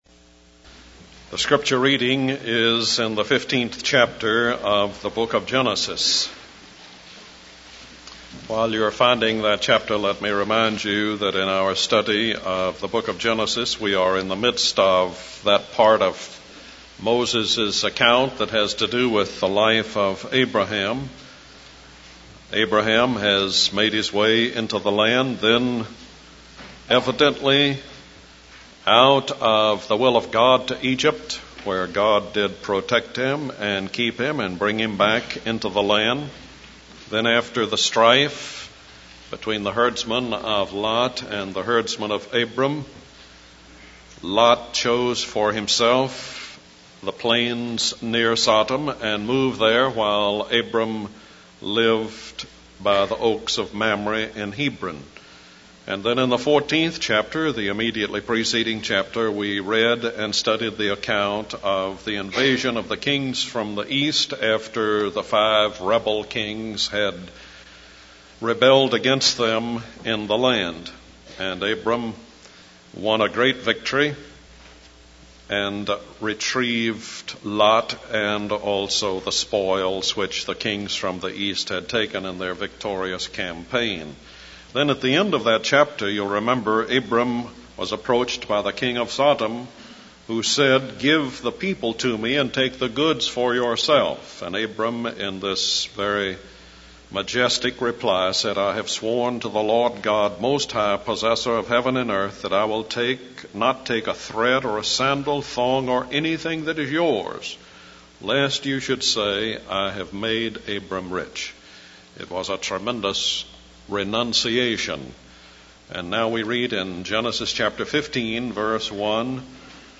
In this sermon, the speaker begins by referencing the previous chapter in the Bible where Abram wins a great victory against the kings from the east and retrieves his nephew Lot.